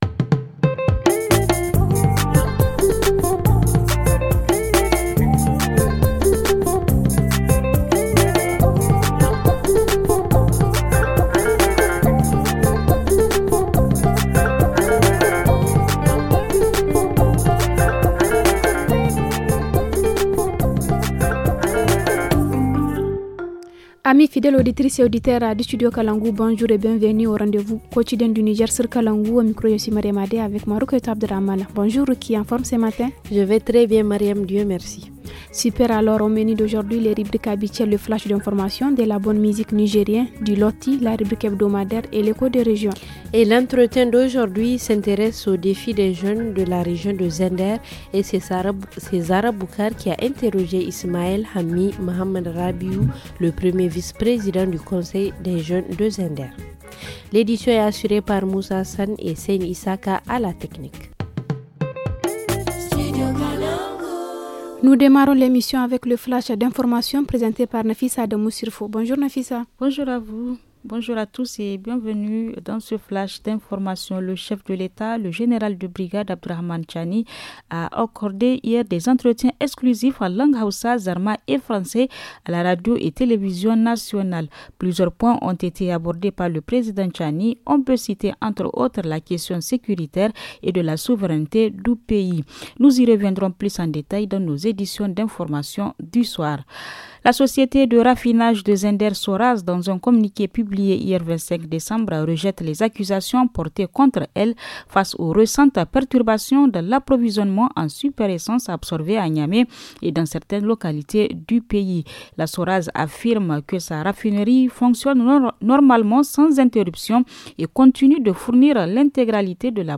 Dans l’émission de ce 26 décembre : entretien sur les défis des jeunes de la région de Zinder. En reportage, à Dogondoutchi, plusieurs écoles souffrent du manque des tables bancs.